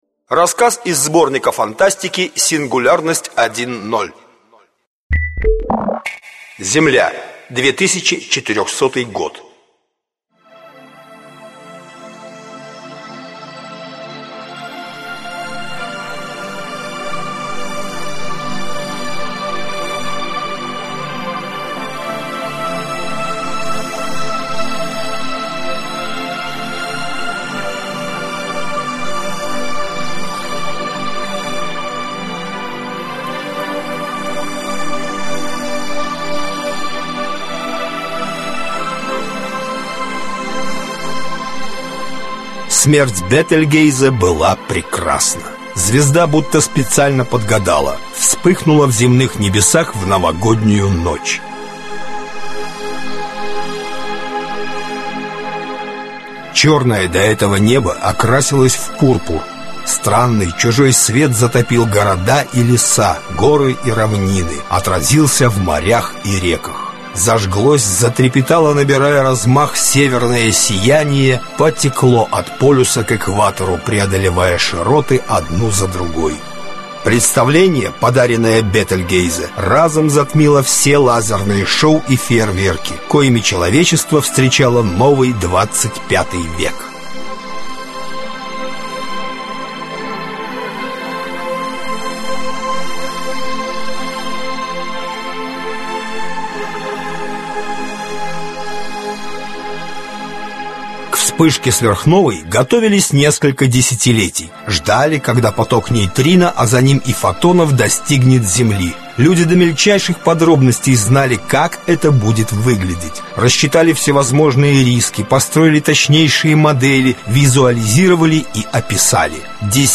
Аудиокнига Убежать от Зенона | Библиотека аудиокниг